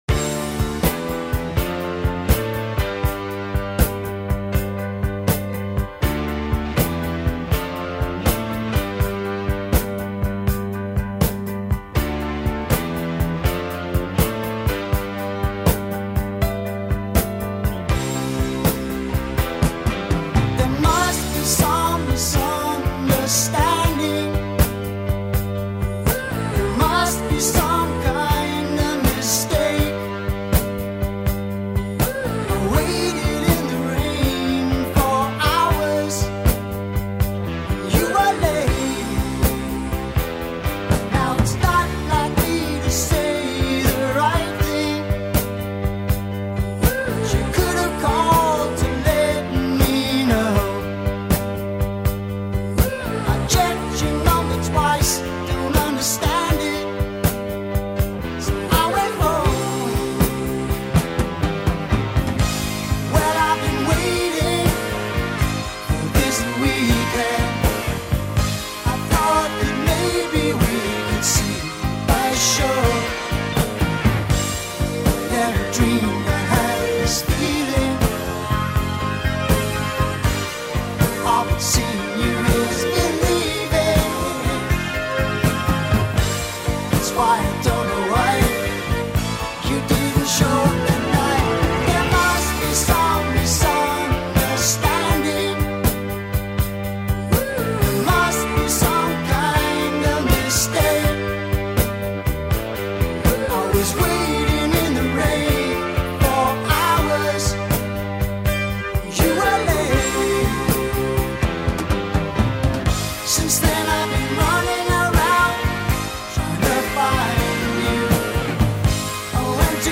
What a great sounding record!